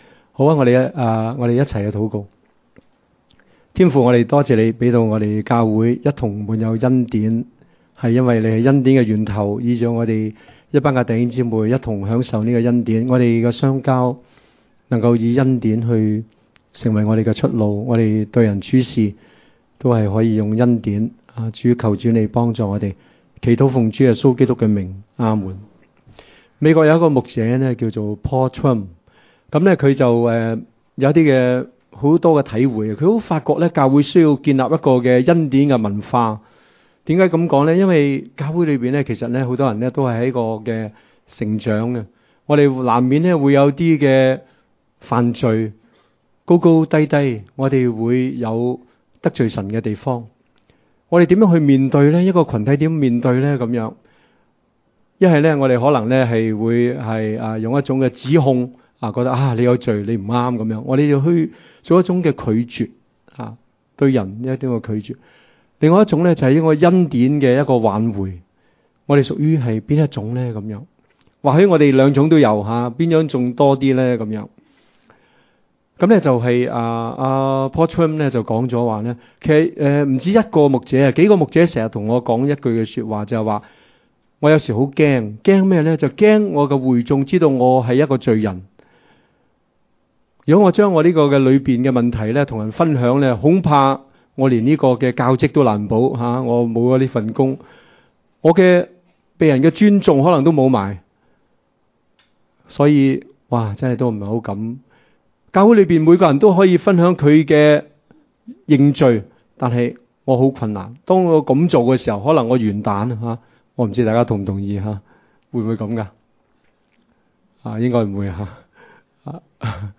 場所：主日崇拜